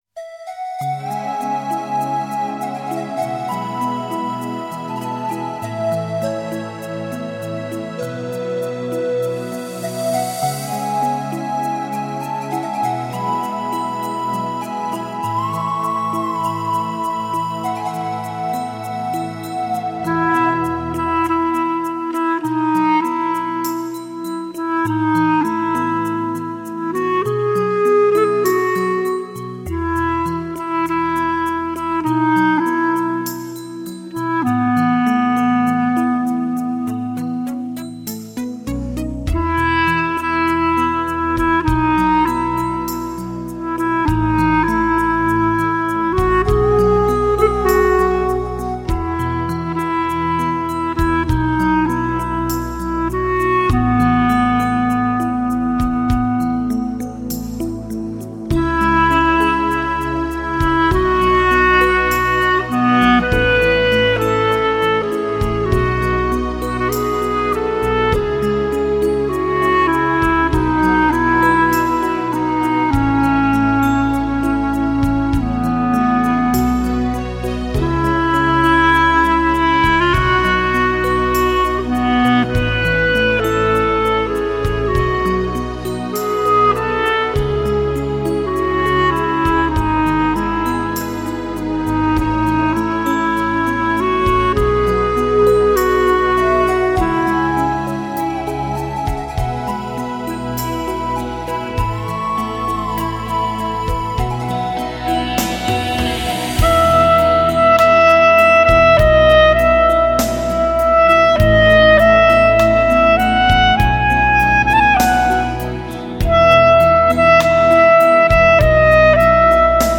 别样的单簧管乐声中，回忆是山温水暖，离别是哀而不伤，牵挂是风情缱绻，爱情是浅浅清清。
单簧管沿着时间的年轮轻吟浅唱。